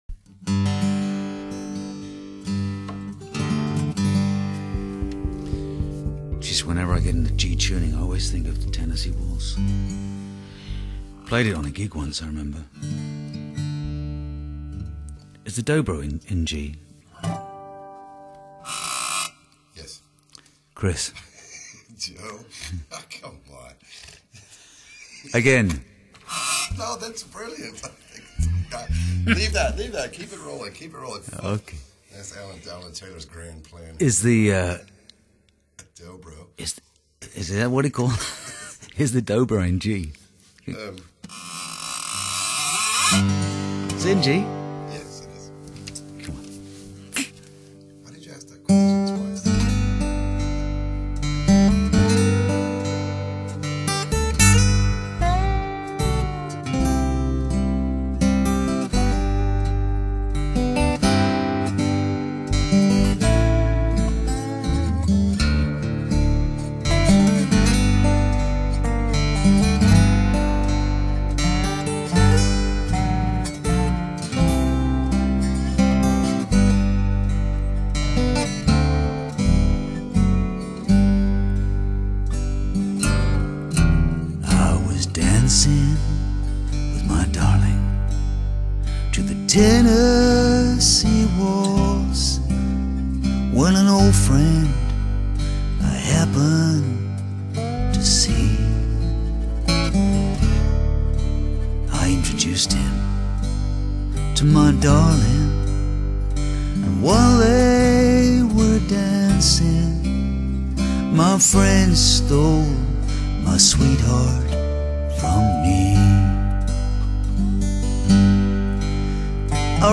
輕柔、穩重、溫暖而抒情
錄音精緻、清澈而透明。